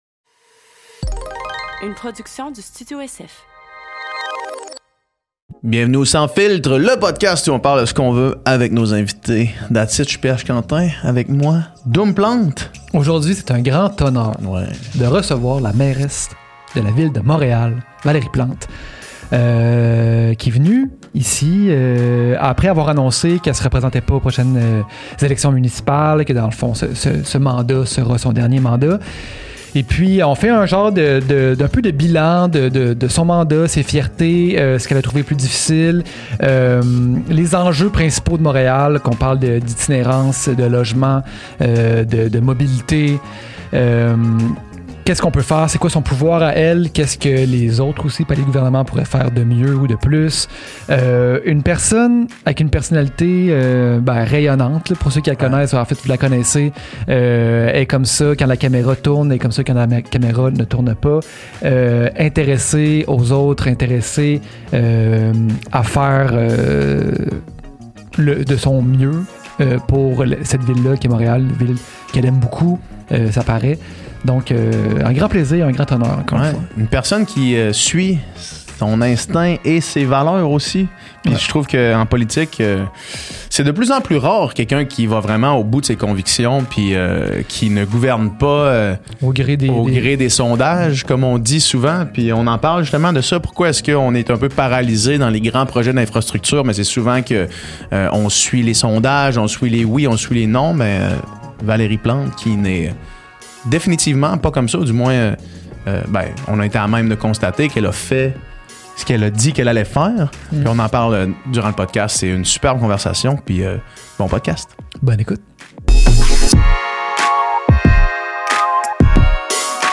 Cette semaine sur le podcast, on reçoit la mairesse de Montréal Valérie Plante. On jase des défis qu\'impliquent gérer une métropole et comment travailler avec un gouvernement provincial et fédéral.